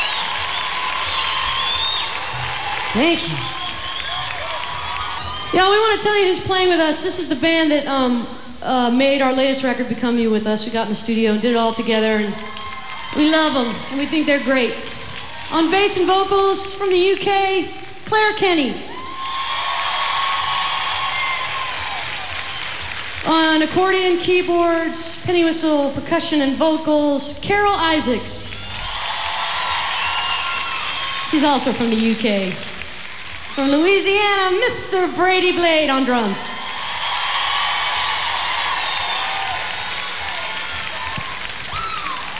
lifeblood: bootlegs: 2002-07-04: petrillo bandshell - chicago, illinois
(band show)
13. talking with the crowd (0:42)